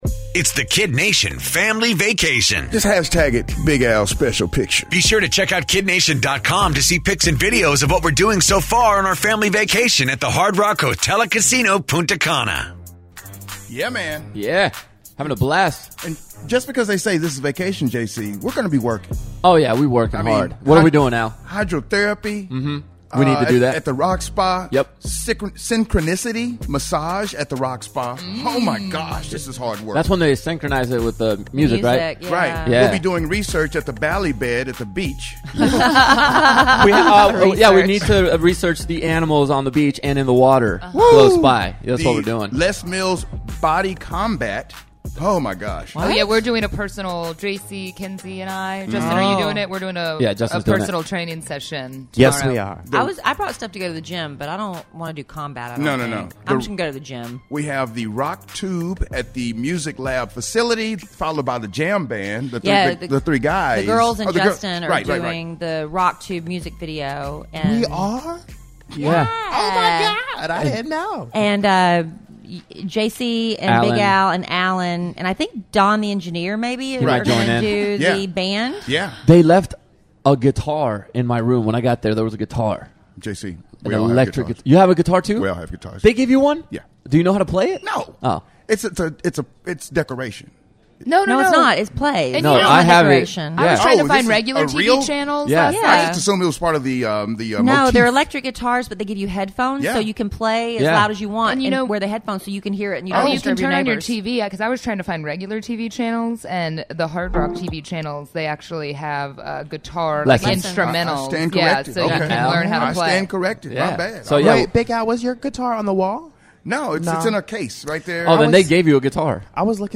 Live From Punta Cana! Day One Of The KiddNation Family Vacation!